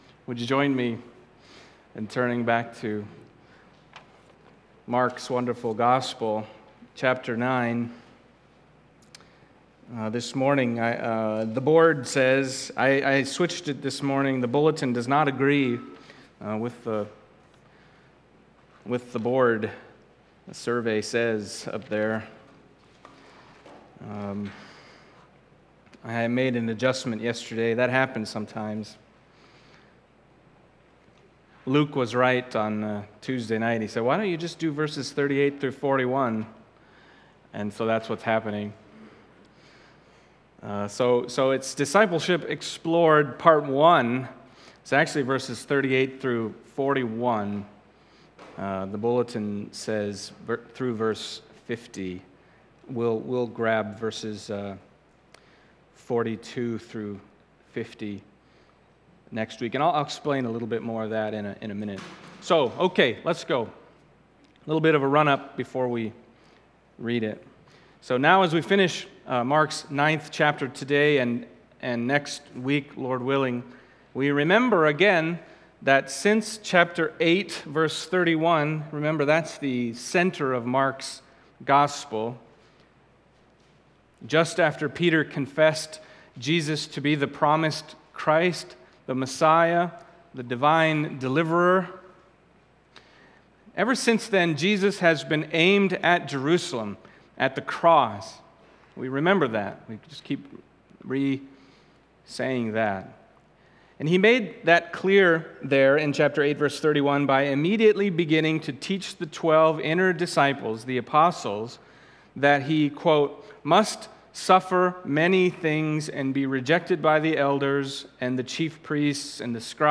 Mark Passage: Mark 9:38-41 Service Type: Sunday Morning Mark 9:38-41 « The Cross and True Greatness Discipleship Explored